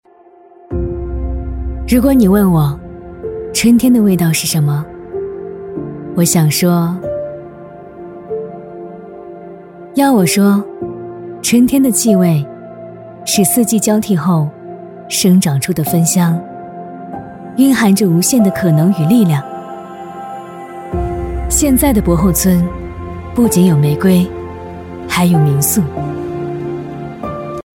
女B32-宣传-【走心 大气 】
女B32-大气质感 年轻舒缓
女B32-宣传-【走心 大气 】.mp3